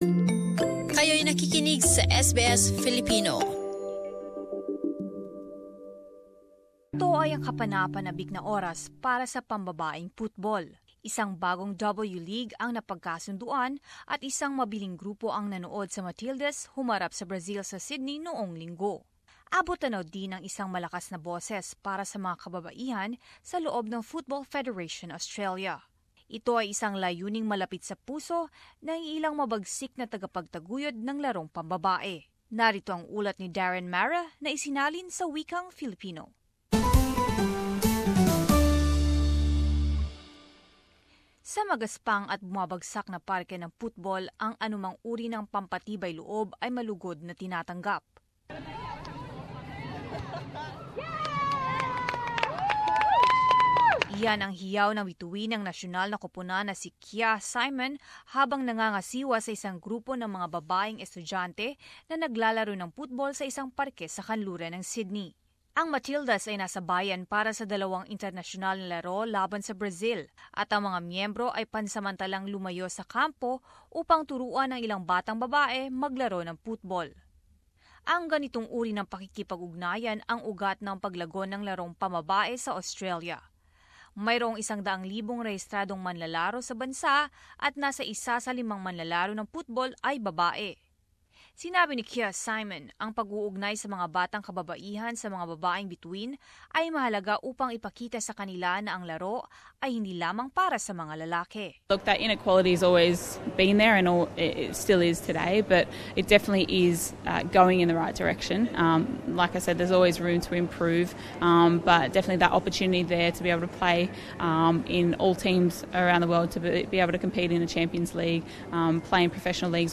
As this report shows, it is a goal close to the hearts of some of the women's game's fiercest advocates.